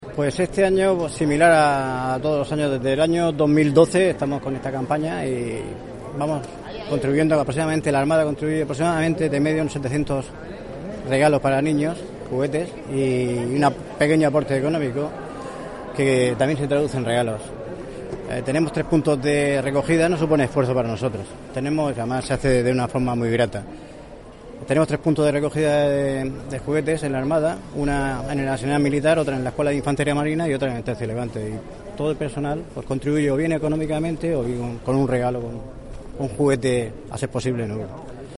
Audio: Declaraciones de Ana Bel�n Castej�n en el acto de clausura de la campa�a de recogida de juguetes (MP3 - 773,11 KB)